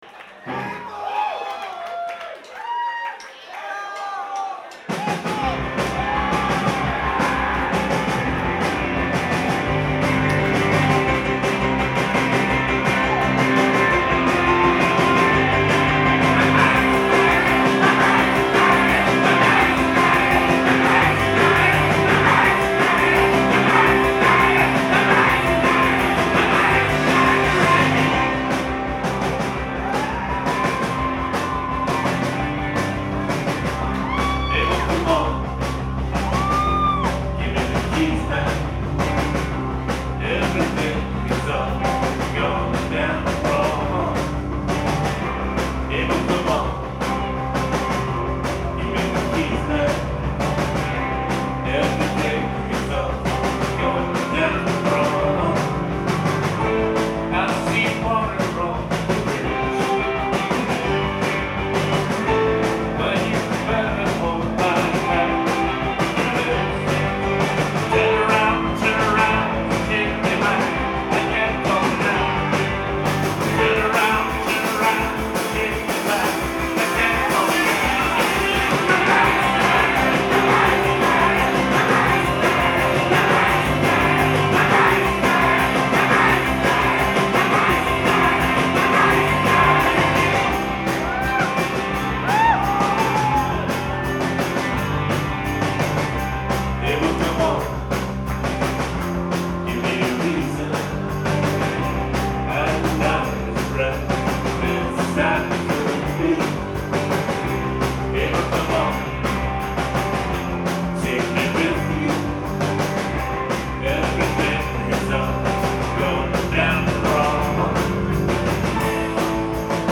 live in Boston